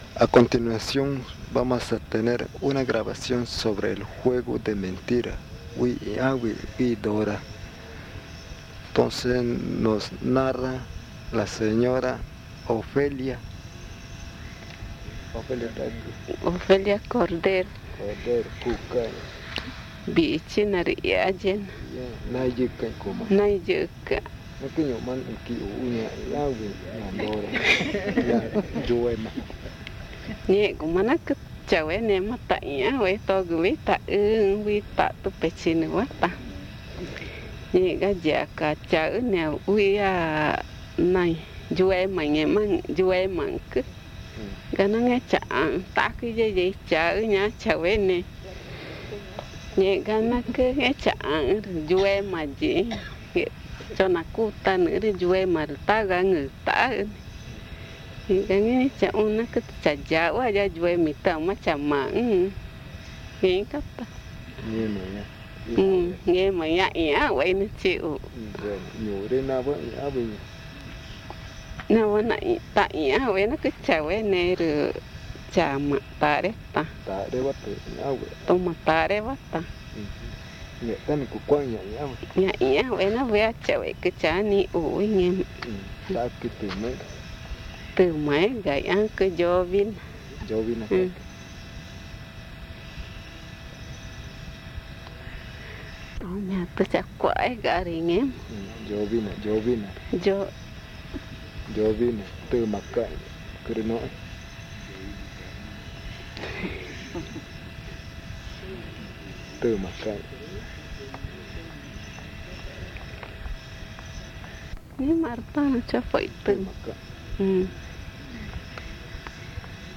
San Antonio de los Lagos, Amazonas (Colombia)